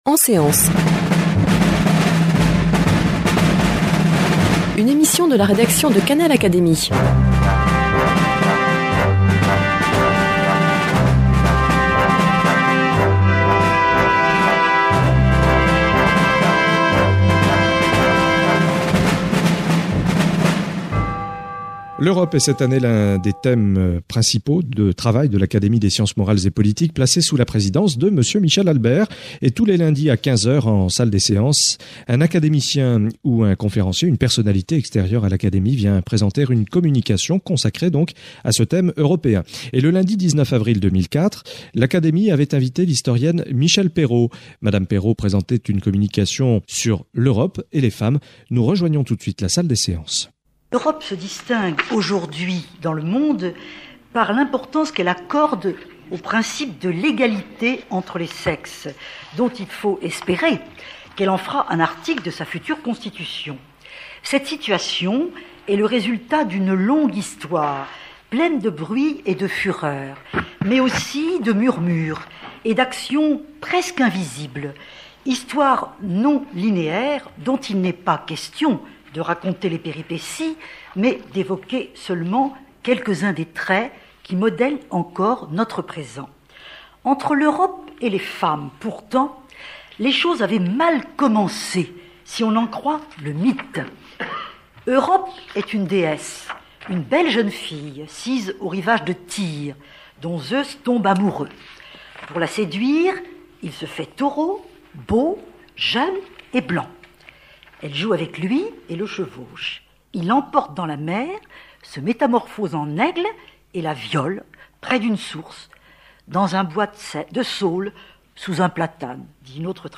Communication de Michèle Perrot prononcée en séance publique devant l’Académie des sciences morales et politiques le lundi 19 avril 2004.